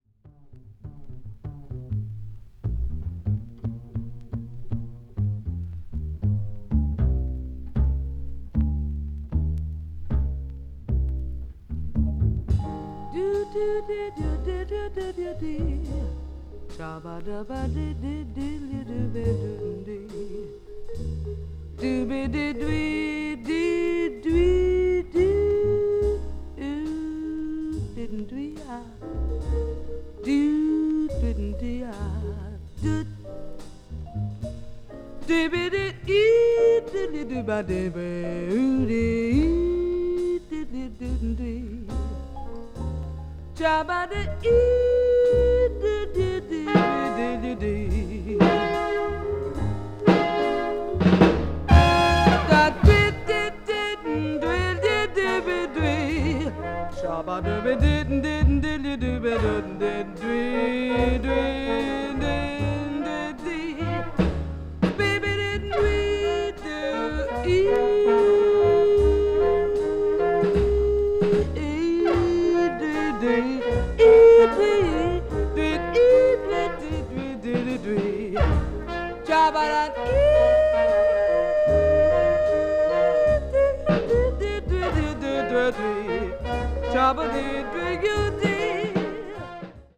a Late Session at an Intimate Club
media : EX-/EX-(薄いスリキズによるわずかなチリノイズ/一部軽いチリノイズが入る箇所あり)
blues jazz   jazz ballad   jazz vocal